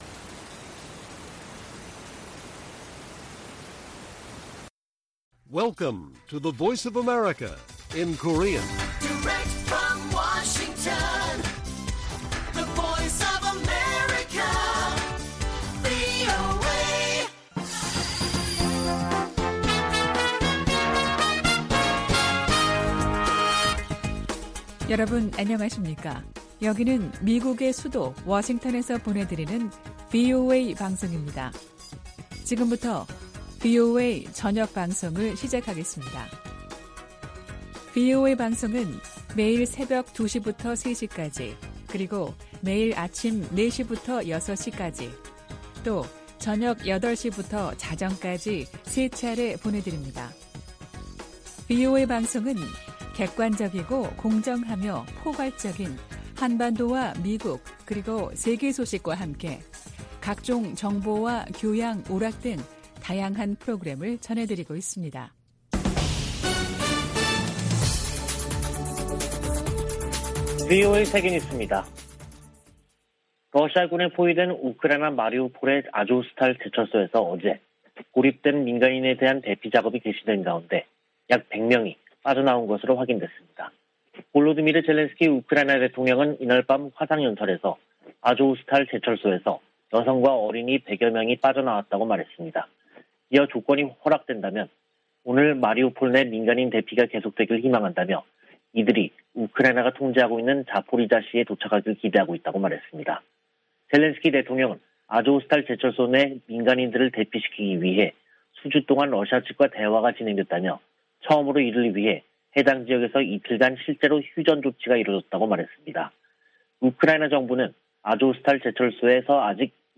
VOA 한국어 간판 뉴스 프로그램 '뉴스 투데이', 2022년 5월 2일 1부 방송입니다. 미 국무부가 핵실험 준비 동향이 포착된 북한에 대해 역내에 심각한 불안정을 초래한다고 지적하고 대화를 촉구했습니다. 북한 풍계리 핵실험장 3번 갱도 내부와 새 입구 주변에서 공사가 활발히 진행 중이라는 위성사진 분석이 나왔습니다. 빌 해거티 미 상원의원은 조 바이든 대통령의 한일 순방이 인도태평양 지역 적국들에 중요한 신호를 보낼 것이라고 밝혔습니다.